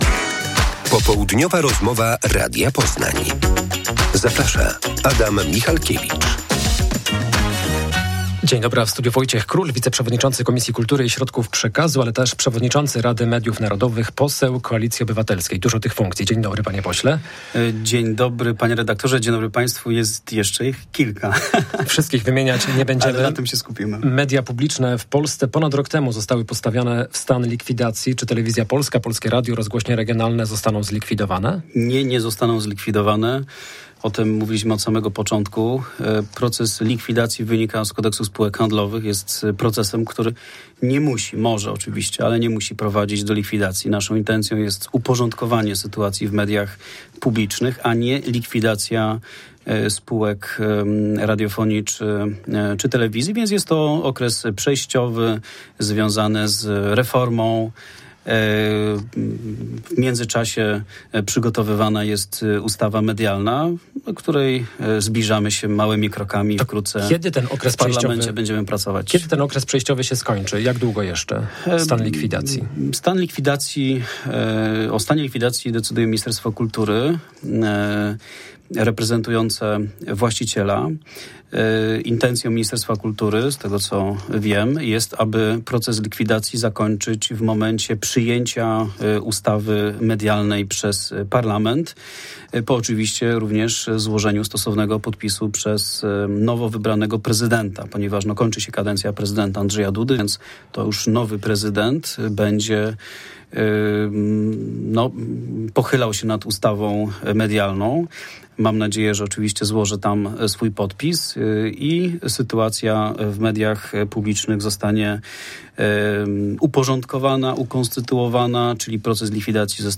Popołudniowa rozmowa Radia Poznań – Wojciech Król